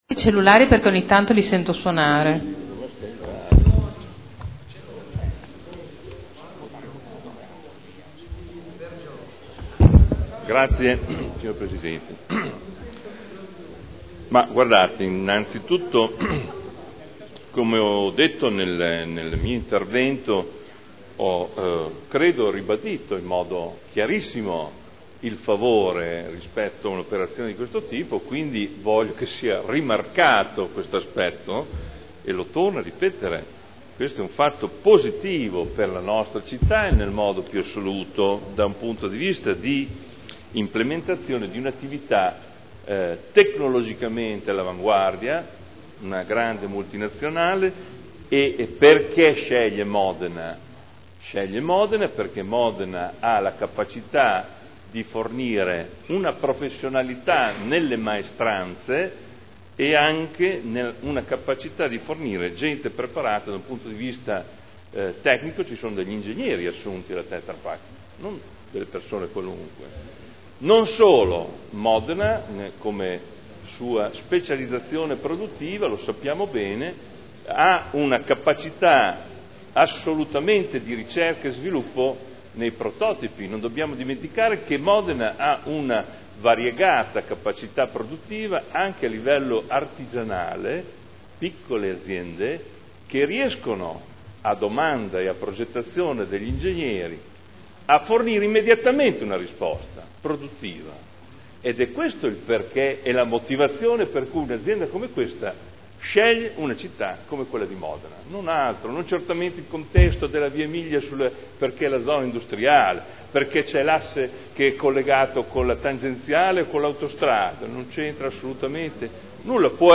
Seduta del 13/12/2010 Deliberazione: Variante al P.O.C.-RUE – AREA in via Emilia Ovest Z.E. 1481-1502 – Adozione Dichiarazioni di voto